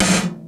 Tom-02.wav